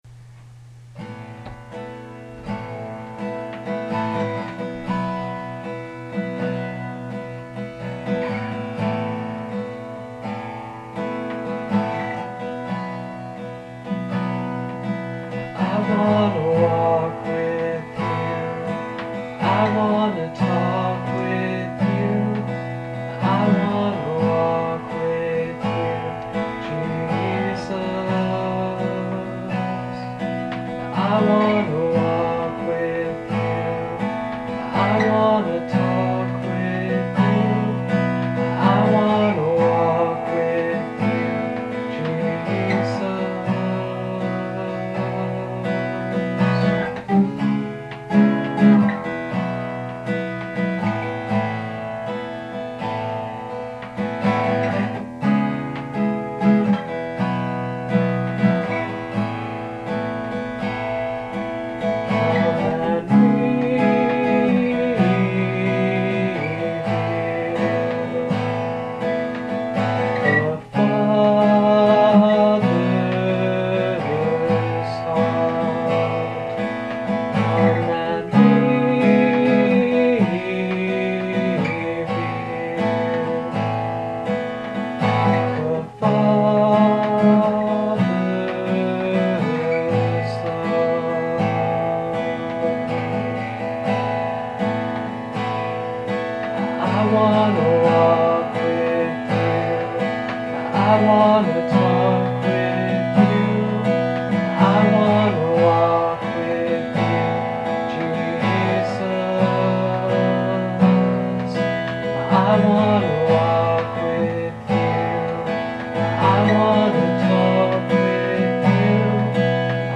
Recorded on my roommate's laptop.